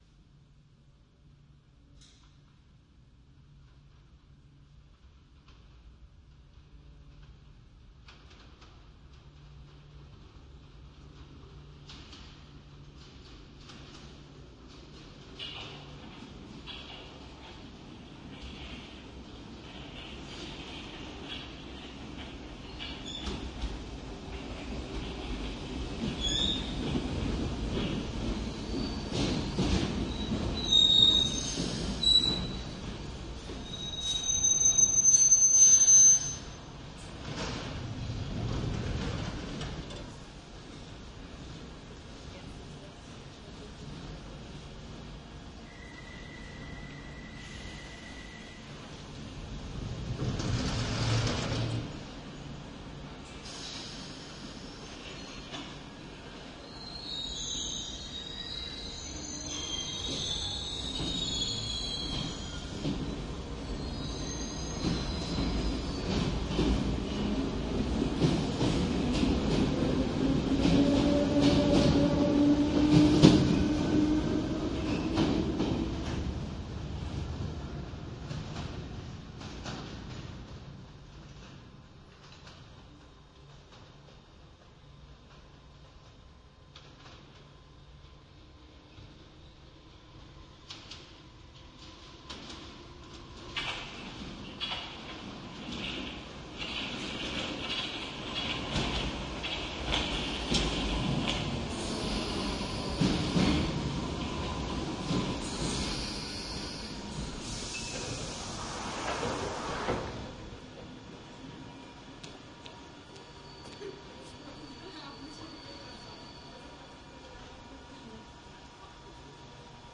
地下列车双耳
描述：双耳记录了伦敦地铁列车从左到右的两次通行。耳机必不可少
Tag: 火车 双耳 地下